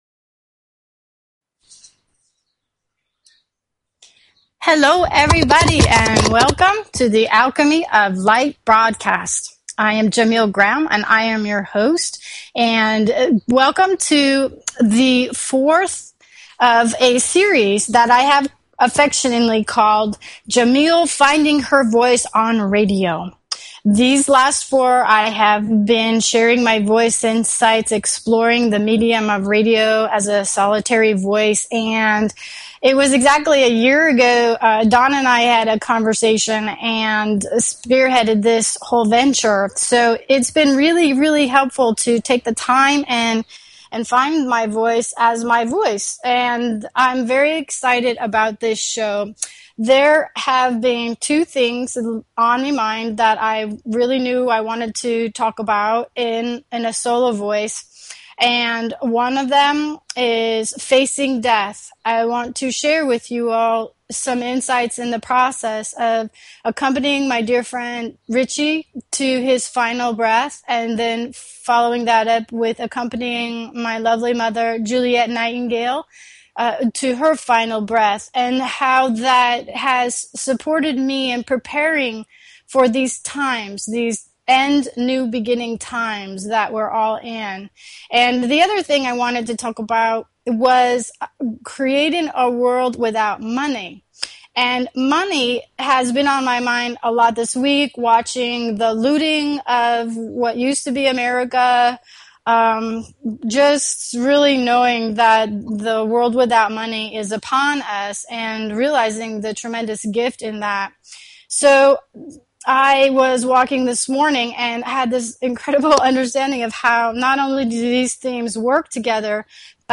Talk Show Episode, Audio Podcast, Alchemy_of_Light and Courtesy of BBS Radio on , show guests , about , categorized as